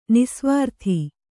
♪ nisvārthi